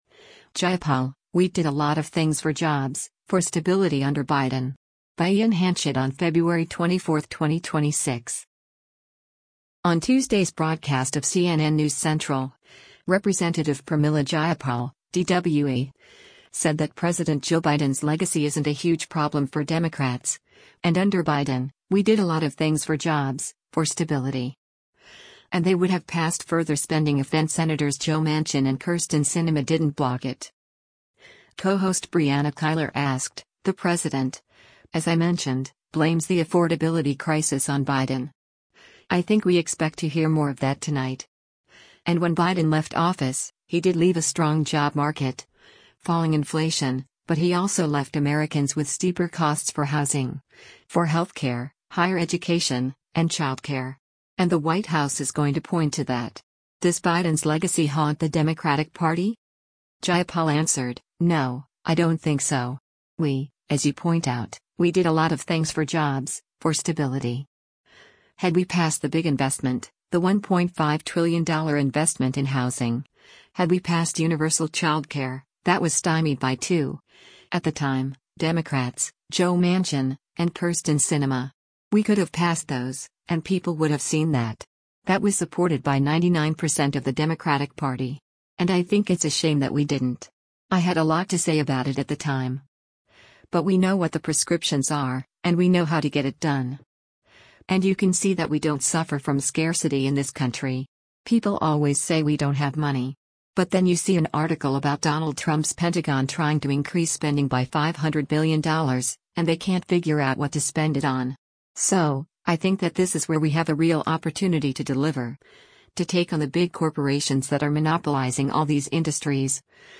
On Tuesday’s broadcast of “CNN News Central,” Rep. Pramila Jayapal (D-WA) said that President Joe Biden’s legacy isn’t a huge problem for Democrats, and under Biden, “we did a lot of things for jobs, for stability.” And they would have passed further spending if then-Sens. Joe Manchin and Kyrsten Sinema didn’t block it.